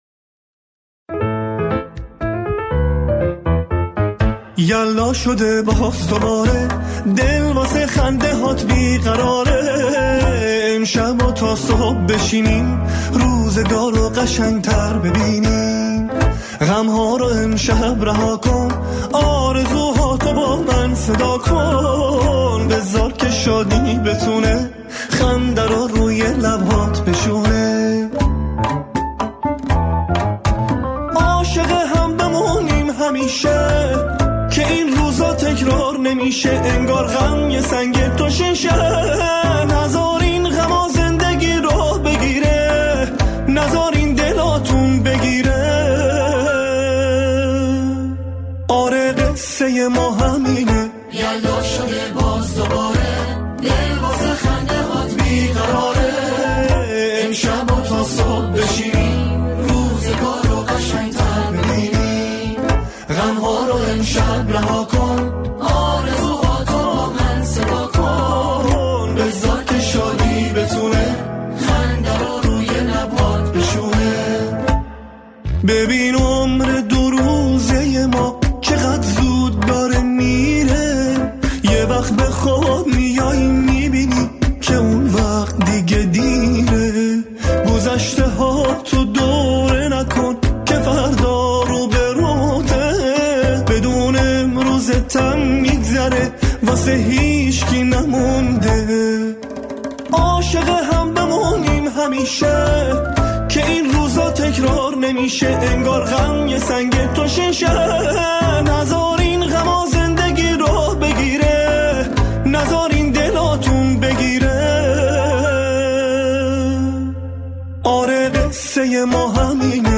با همراهی گروه کر اجرا می‌کند.